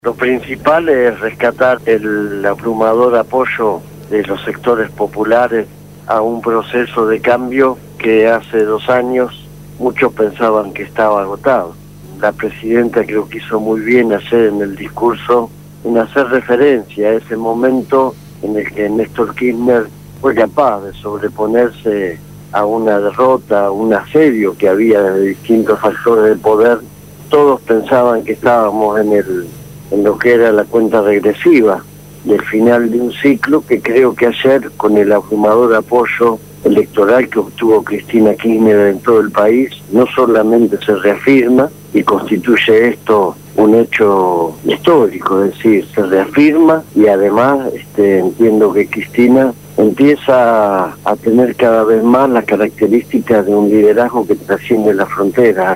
Entrevista a Hugo Yasky, Secretario General CTA de los Trabajadores